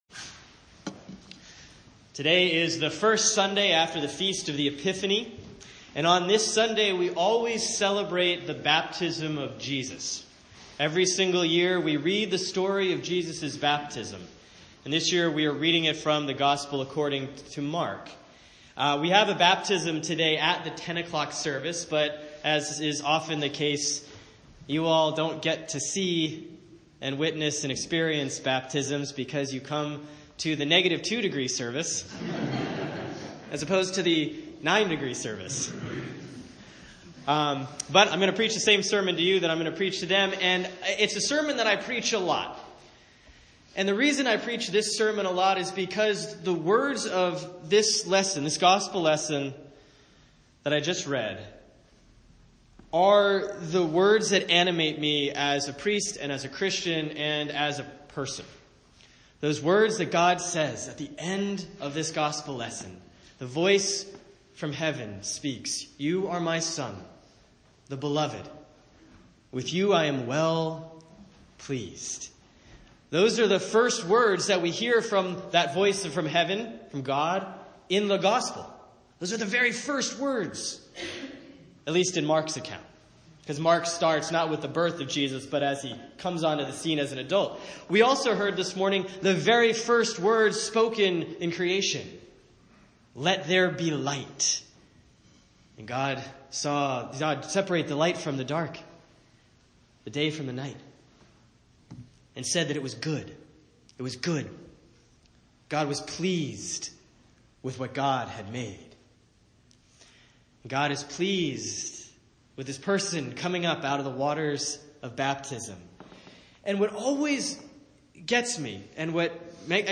The sermon this past Sunday was half talking, half singing.